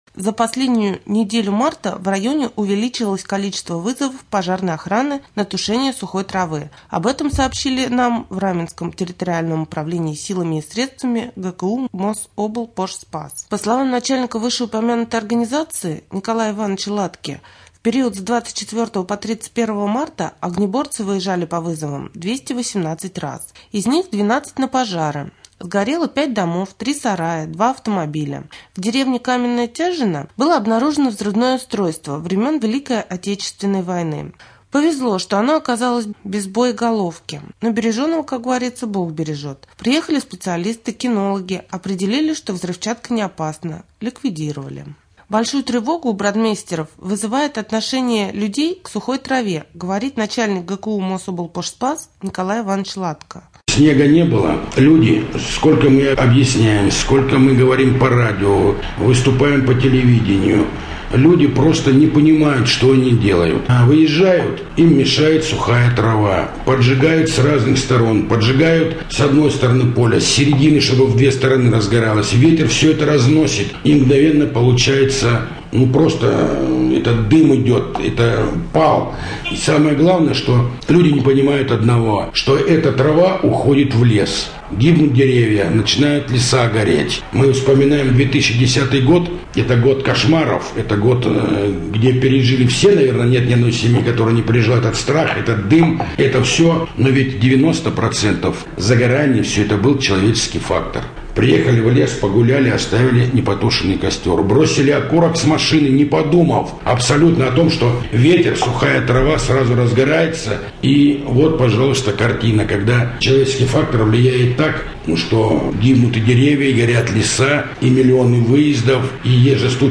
03.04.2014г. в эфире раменского радио - РамМедиа - Раменский муниципальный округ - Раменское
3.Рубрика «Специальный репортаж» Мособлпожспас предостерегает жителей города Раменское: Пал сухой травы — угроза пожара.